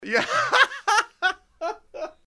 Generic Laugh 1